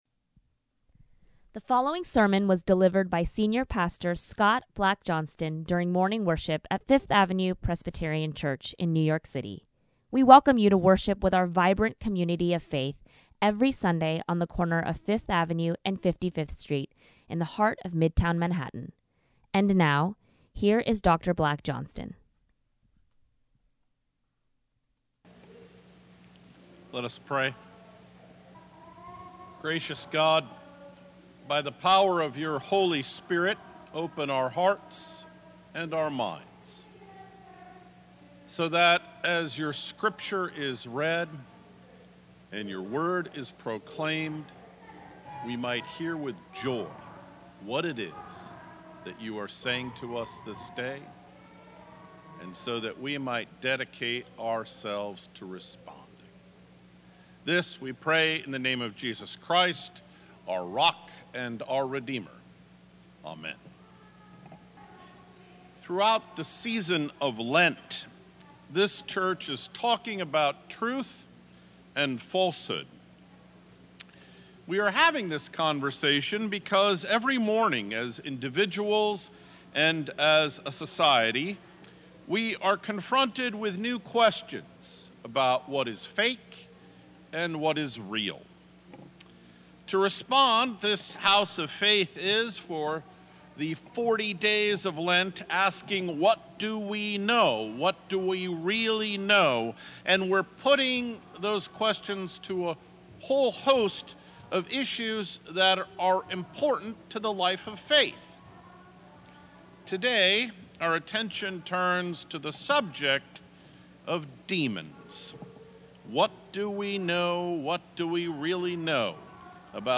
Third Sunday in Lent | Sermons at Fifth Avenue Presbyterian Church